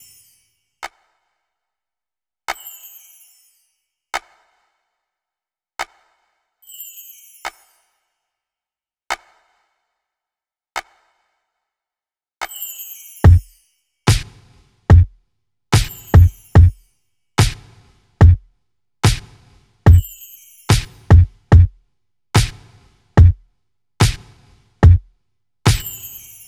02 drums B.wav